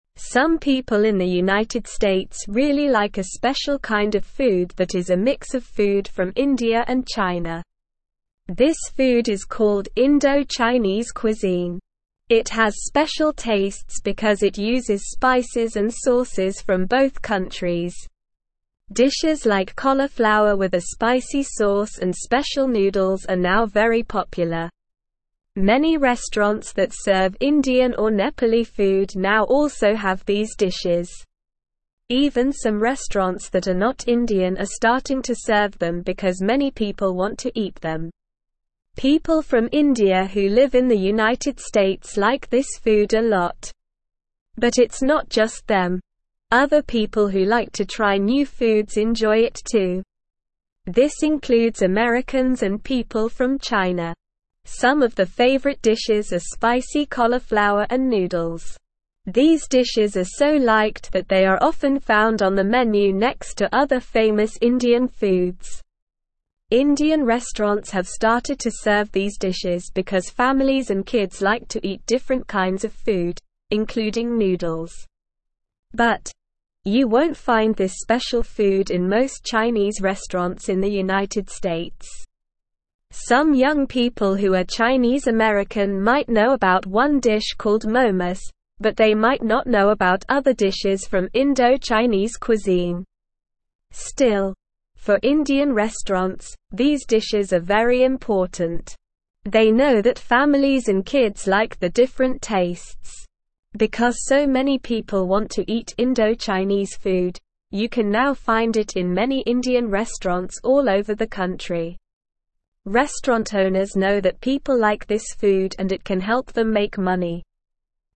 Slow
English-Newsroom-Lower-Intermediate-SLOW-Reading-Indo-Chinese-Food-A-Tasty-Mix-of-India-and-China.mp3